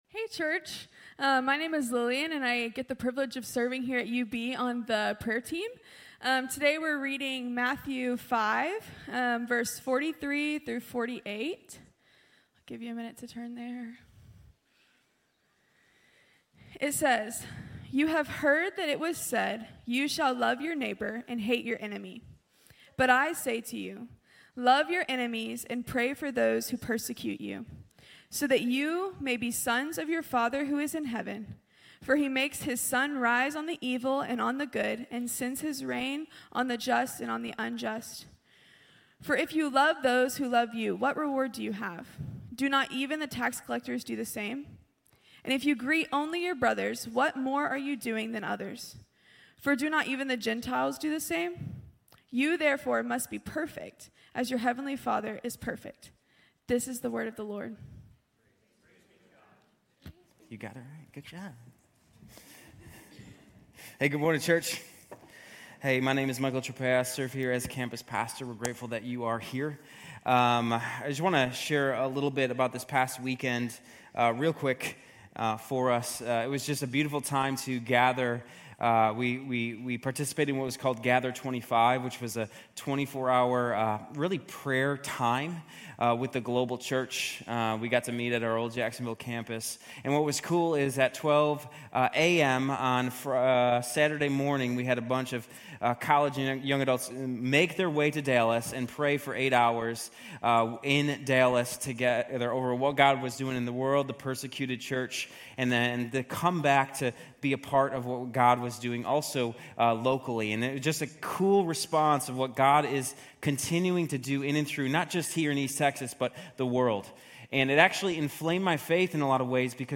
Grace Community Church University Blvd Campus Sermons 3_2 University Blvd Campus Mar 03 2025 | 00:32:57 1x 00:00 / 00:32:57 Subscribe Share RSS Feed Share Link Embed